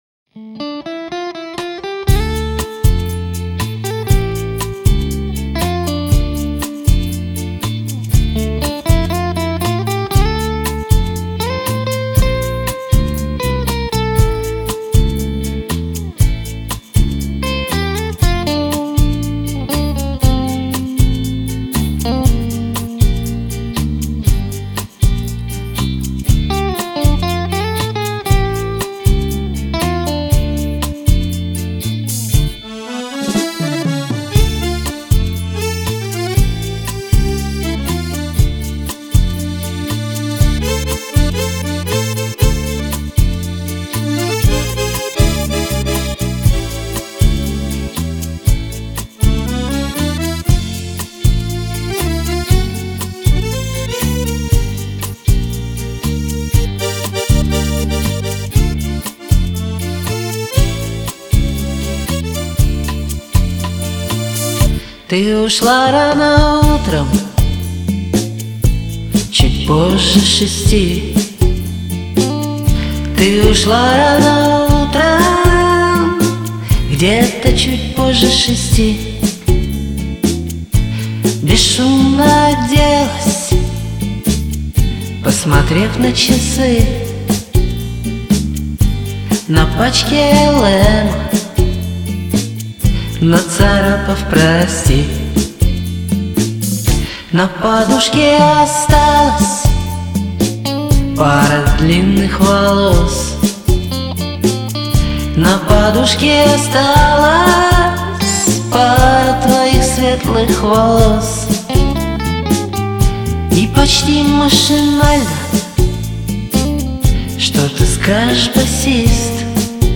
да еще и блюз!